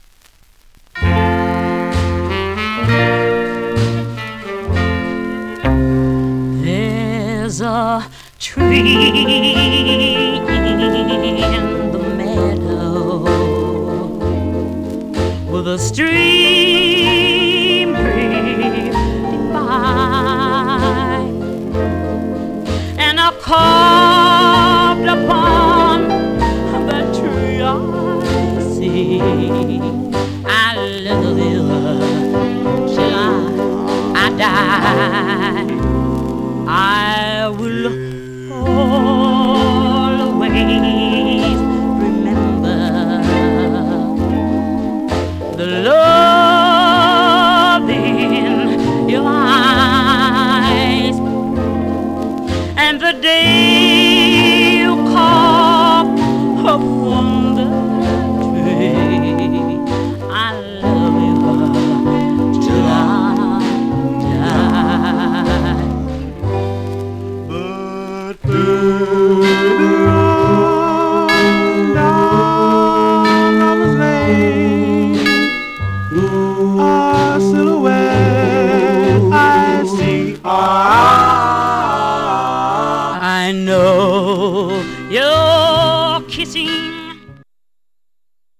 Some surface noise/wear Stereo/mono Mono
Male Black Group